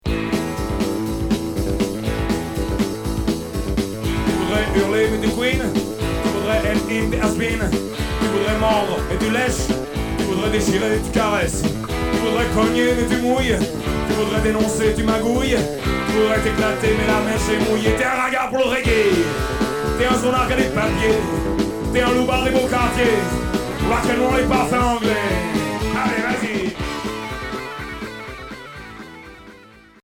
Rock et Reggae